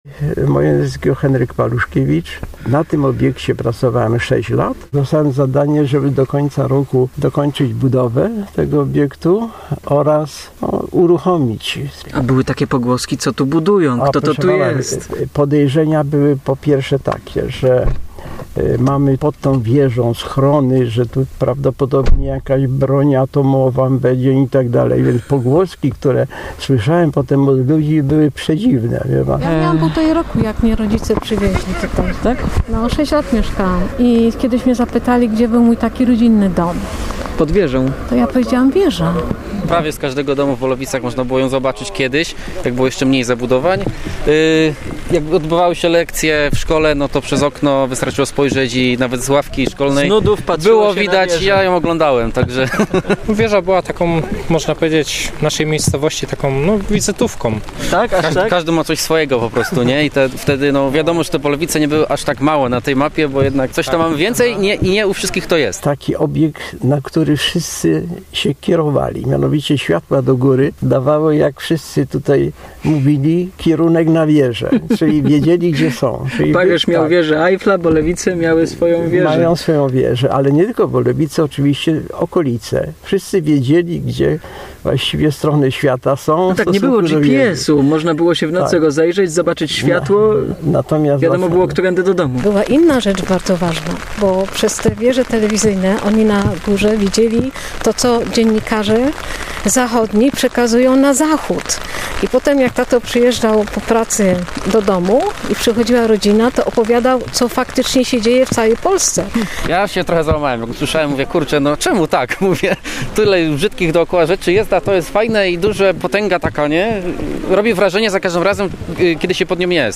- dodawał mieszkaniec miejscowości.